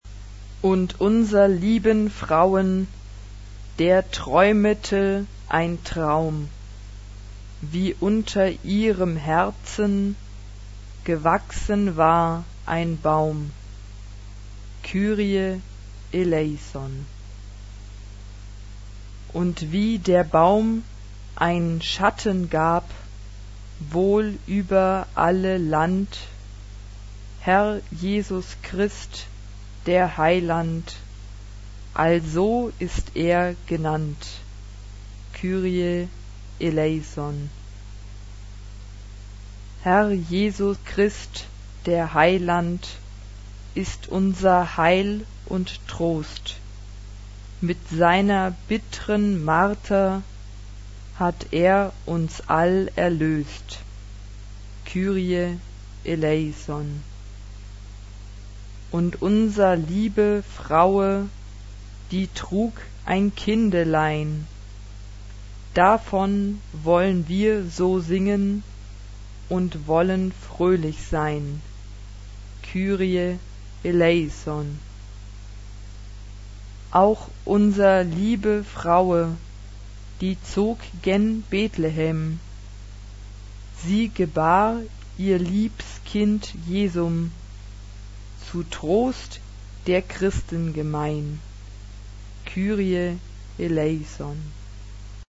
SSAA (4 voix égales de femmes) ; Partition complète.
Sacré. Chœur.